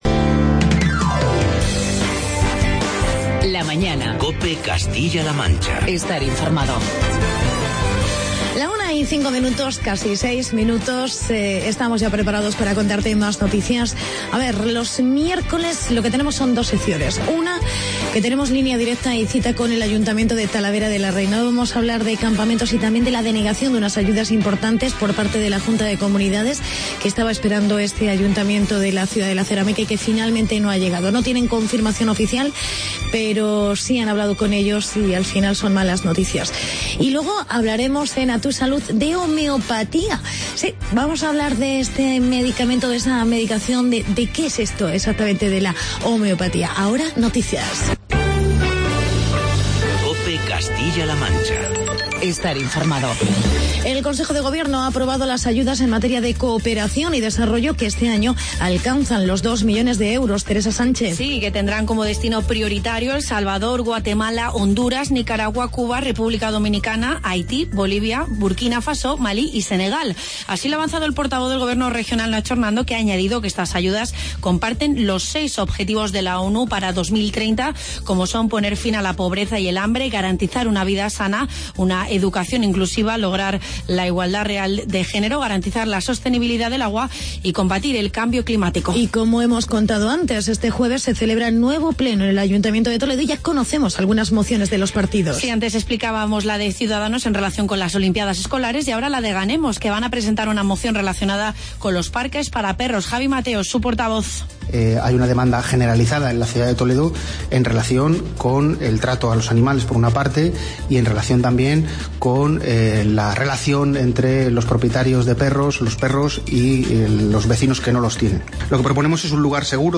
Entrevista con la concejal Ana SantaMaría sobre campamentos urbanos